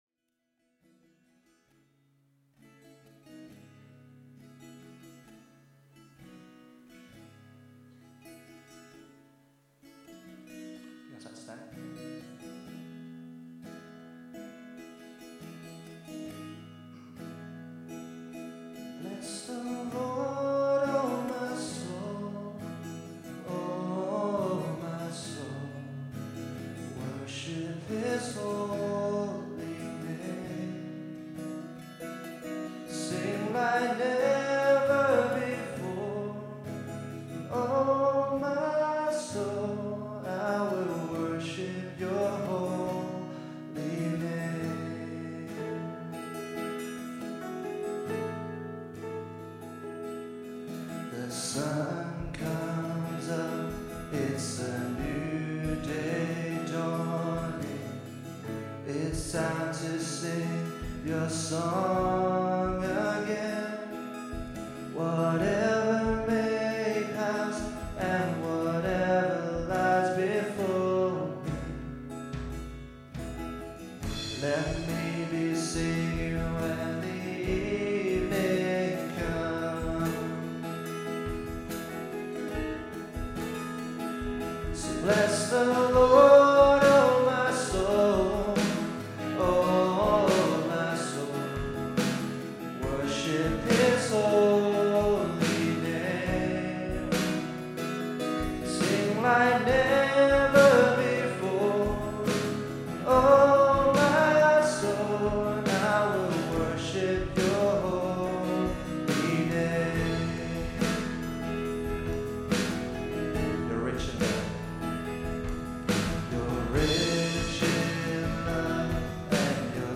Worship September 6, 2015 – Birmingham Chinese Evangelical Church